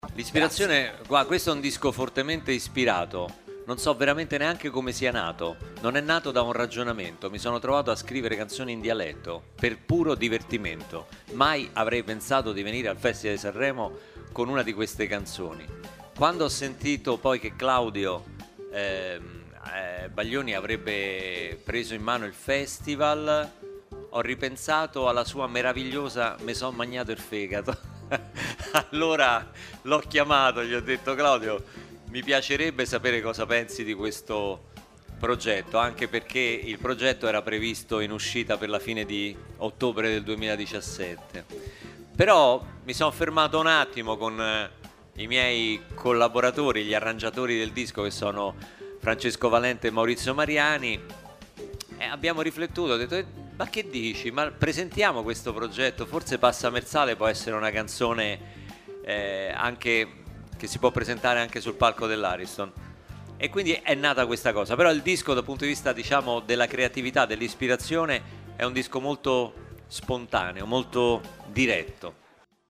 In sala stampa arriva Luca Barbarossa.